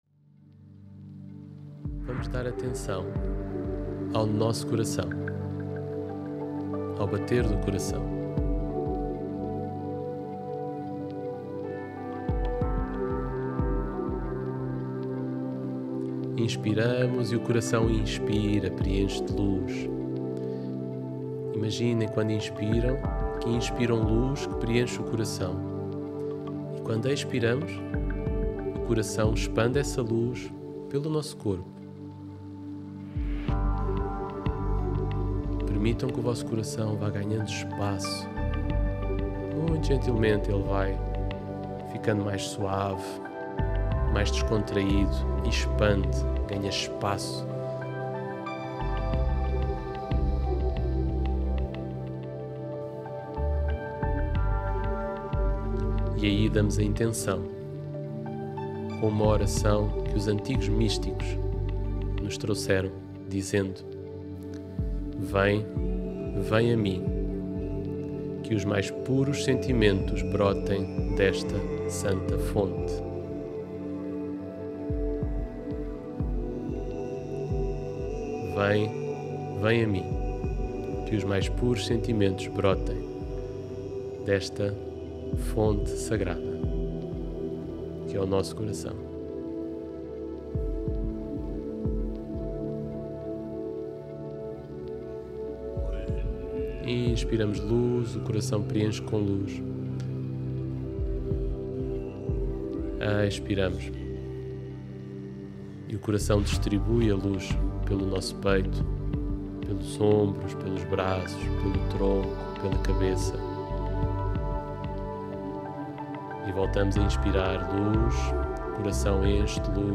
Meditação-Regulação-Emocional-Sistema-Nervoso-16-minutos.mp3